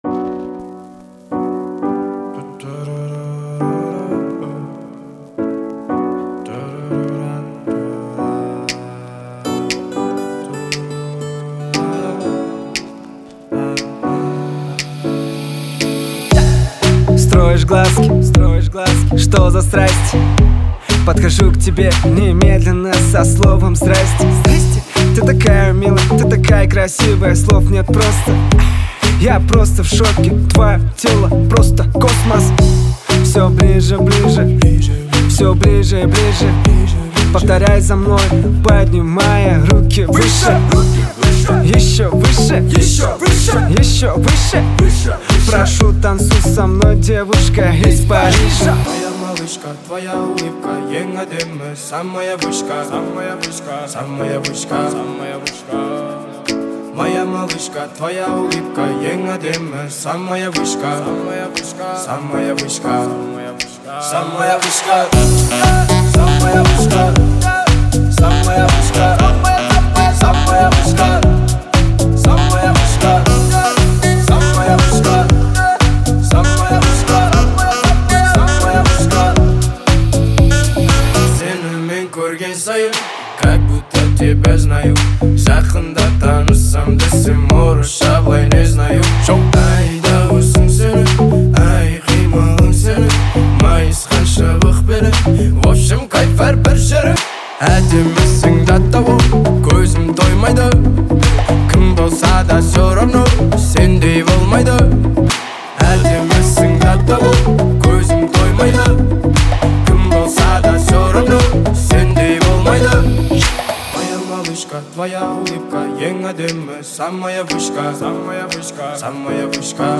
зажигательная песня